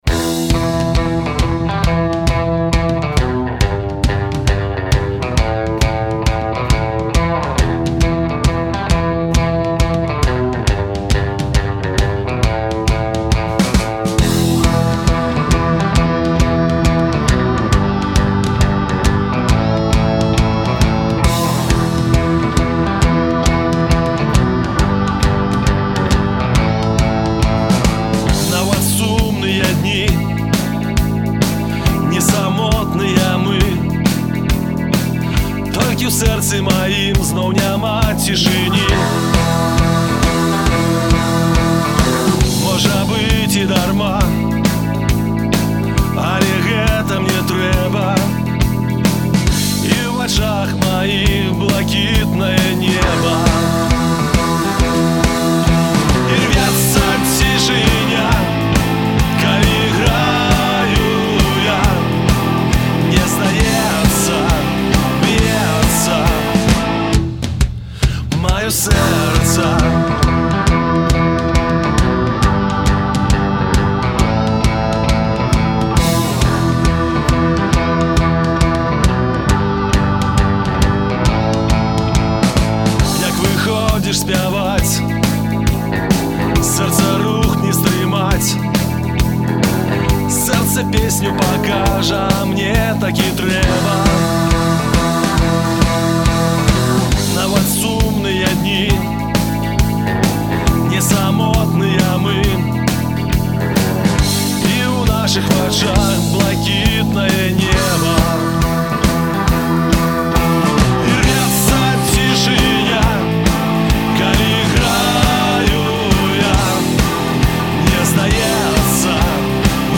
бадзёры і аптымістычны гімн вясьне і жыцьцю.